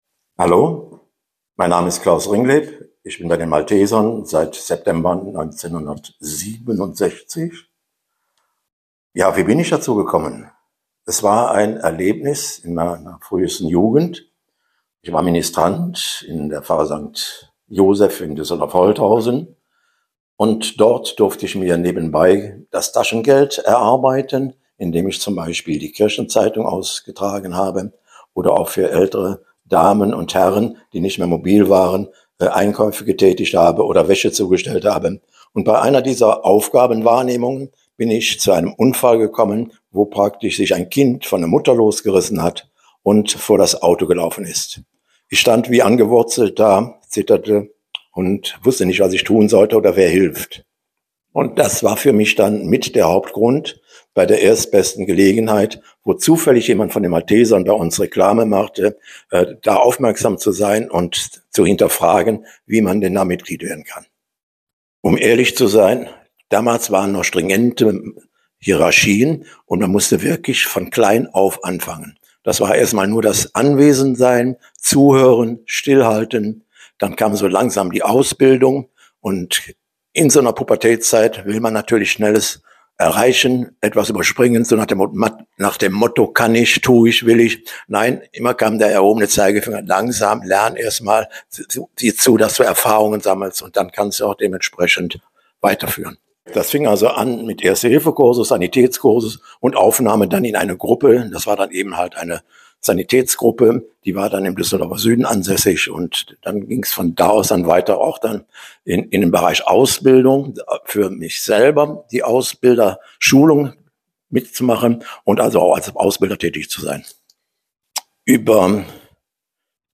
In unserer Audioausstellung erzählen Mitarbeitende und Ehrenamtliche von ihrem Engagement bei den Maltesern in Düsseldorf.